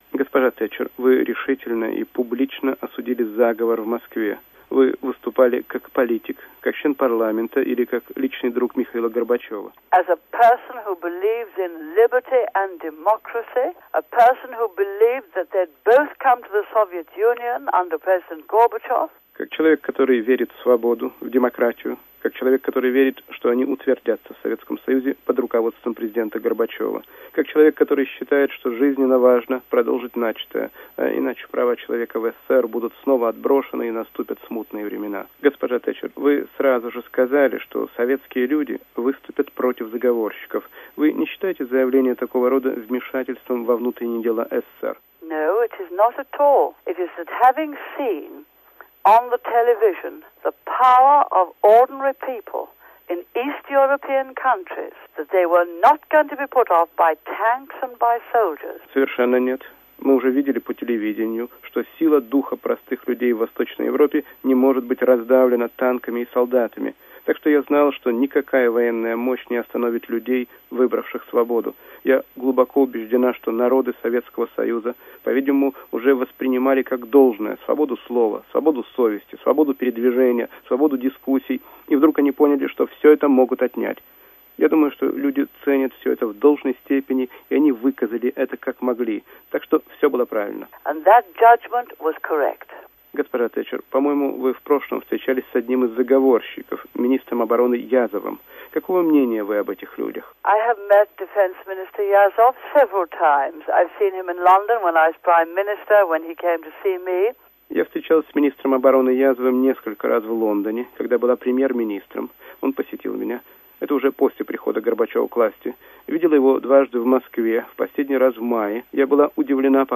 Интервью с Маргарет Тэтчер. Архивная запись "Свободы"